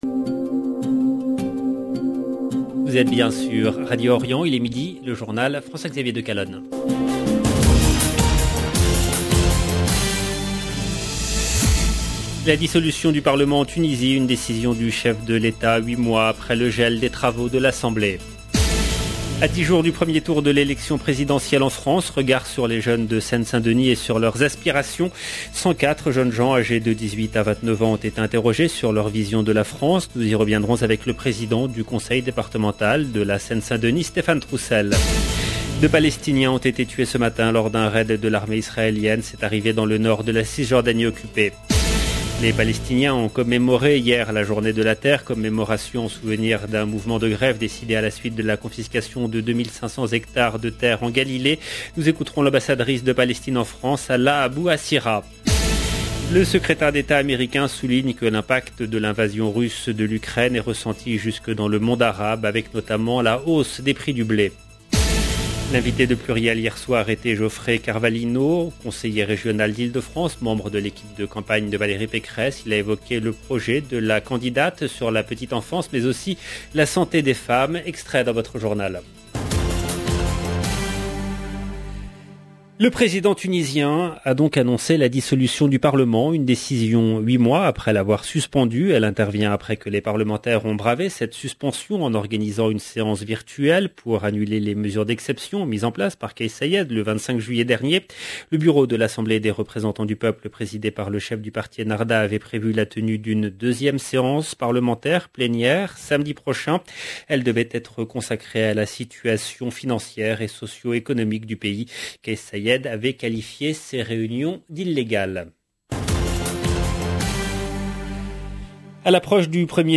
LE JOURNAL EN LANGUE FRANCAISE DE MIDI DU 31/03/22 LB JOURNAL EN LANGUE FRANÇAISE